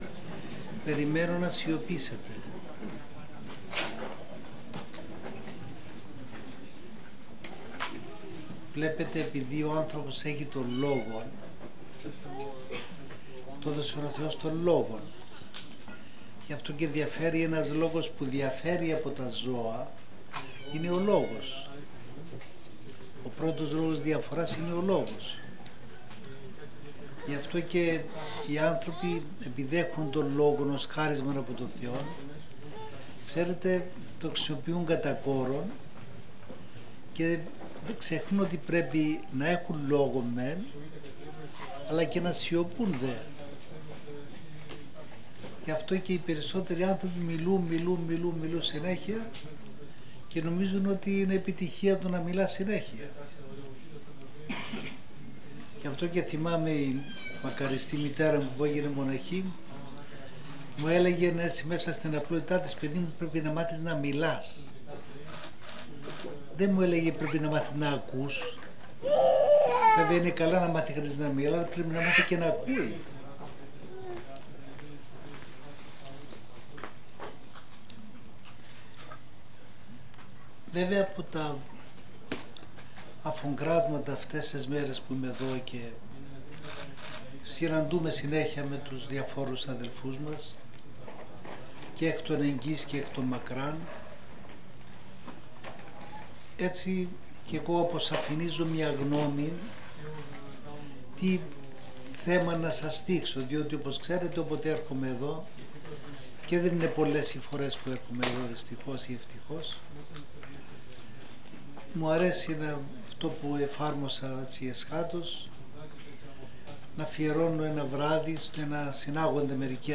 πνευματική ομιλία σε πιστούς
Μετά το πέρας της ομιλίας οι παρευρισκόμενοι ακροατές έκαναν ερωτήσεις